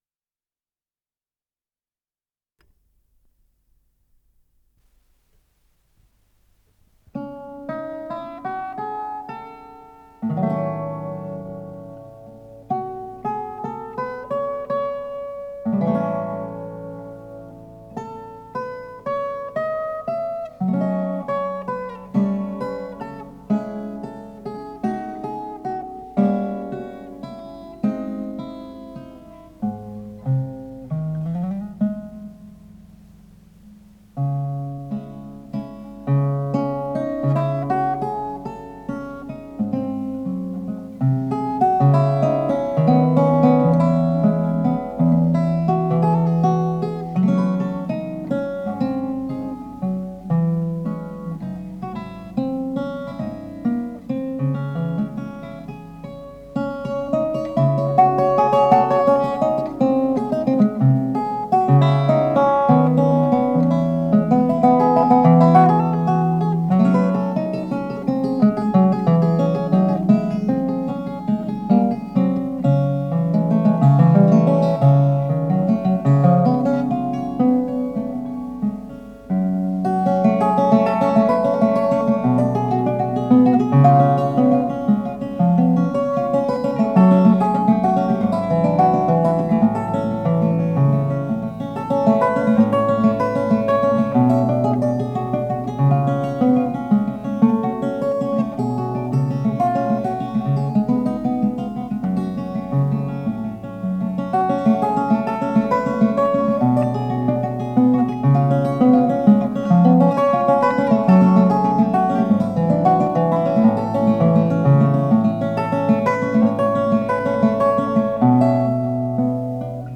7-миструнная гитара
ВариантДубль моно